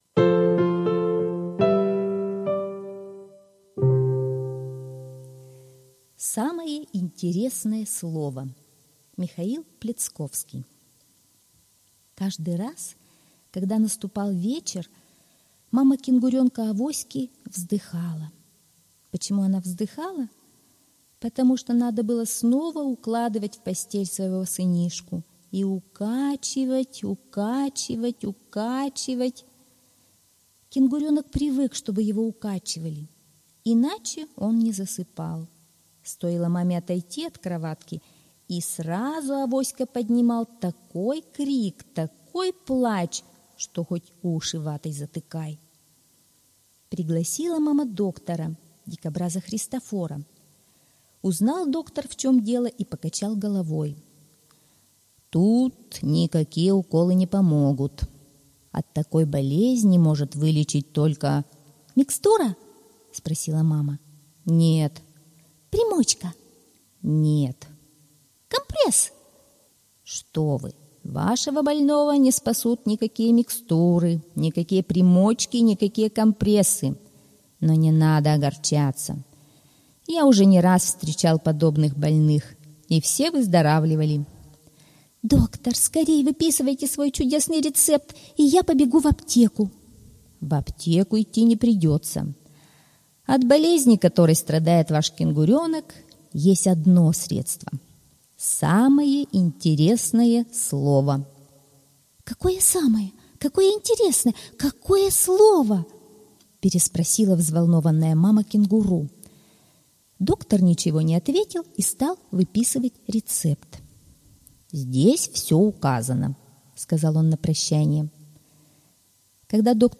Самое интересное слово - аудиосказка Пляцковского М.С. Короткая сказка про неугомонного кенгуренка, который плохо спал.